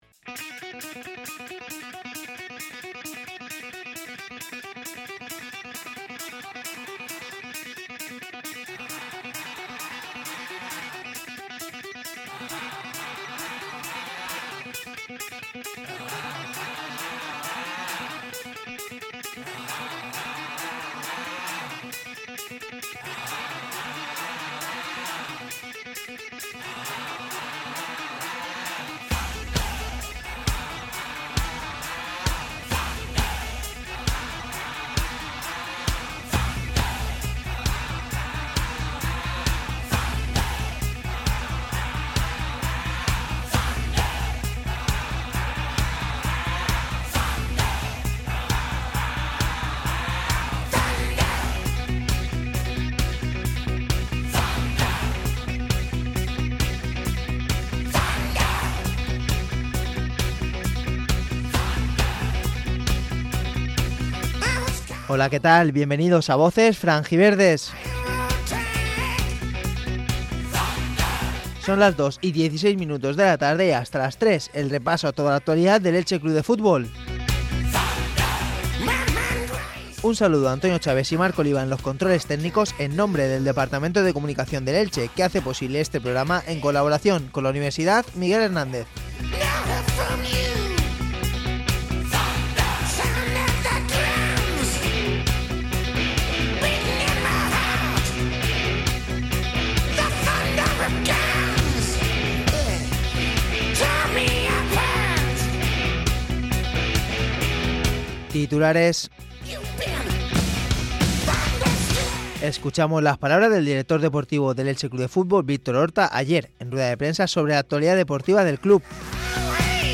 Programa deportivo sobre la actualidad del Elche C.F. que se emite en directo los lunes y los viernes a las 14.10h. es un programa del departamento de comunicación del Elche CF